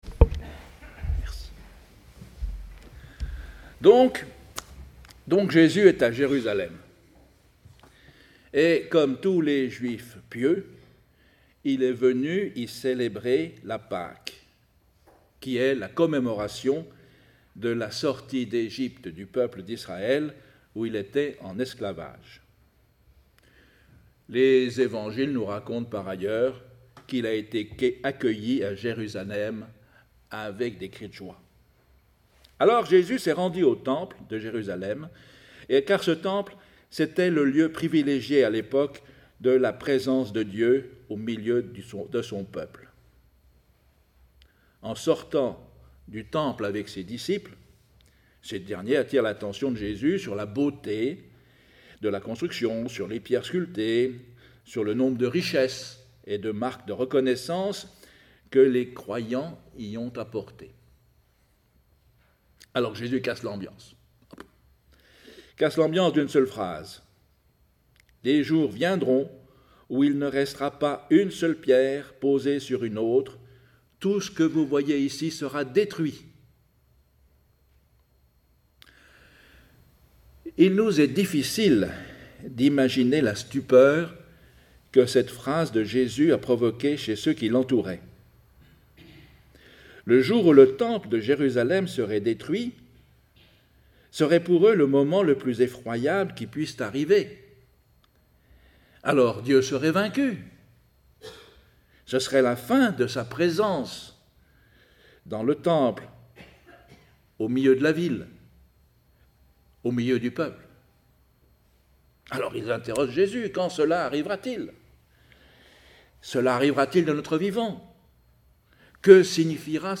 Podcasts prédications